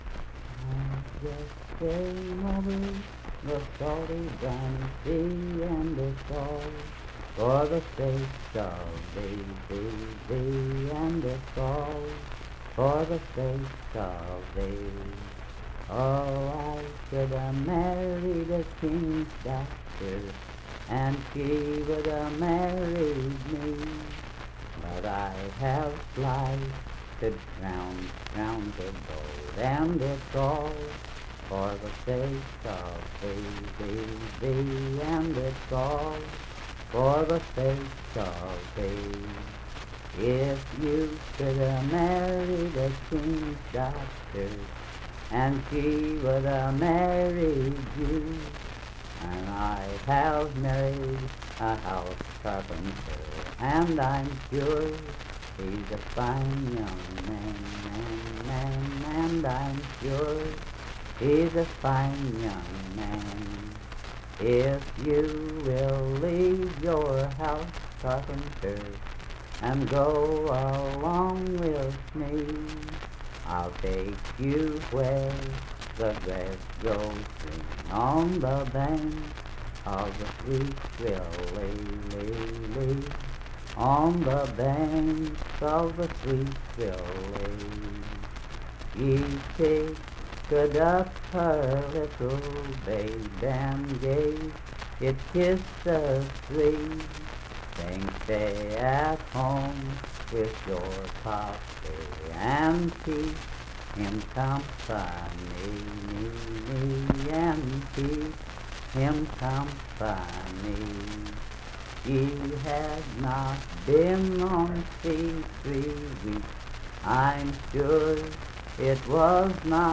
Unaccompanied vocal music
Verse-refrain 7(5w/R).
Voice (sung)
Clay County (W. Va.), Clay (W. Va.)